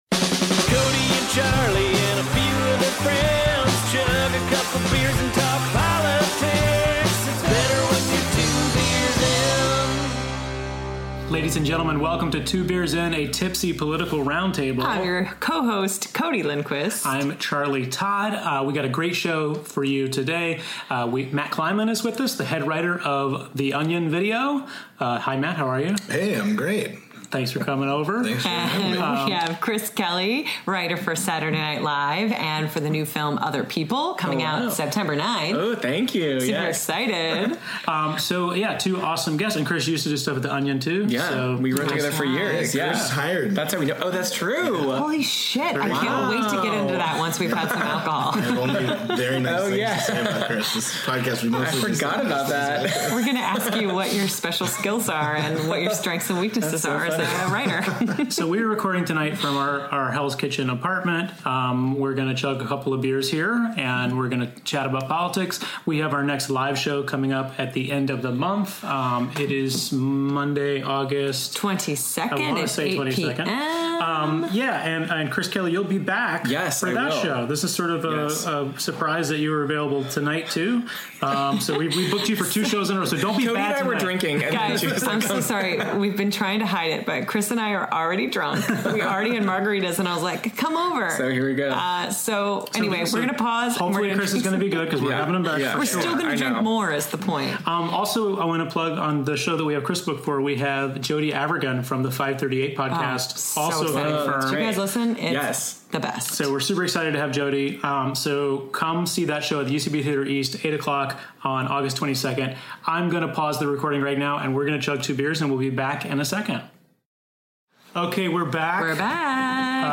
tipsy conversation